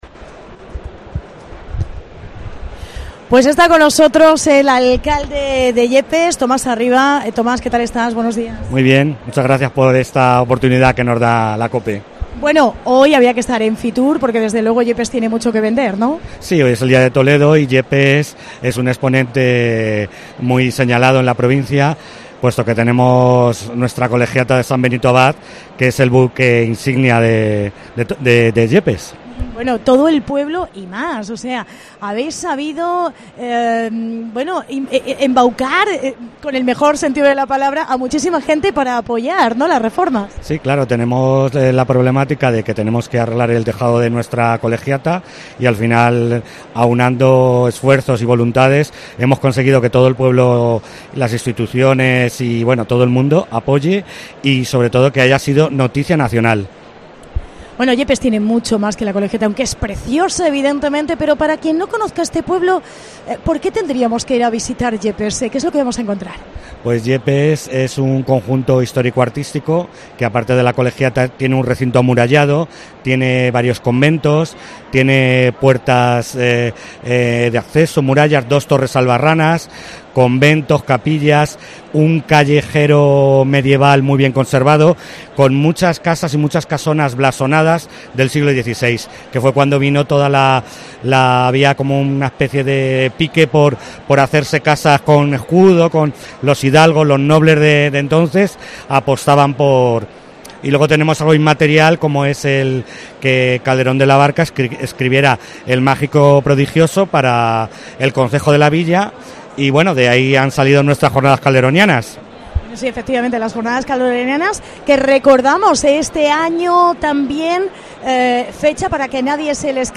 FITUR | Entrevista a Tomás Arribas, alcalde de Yepes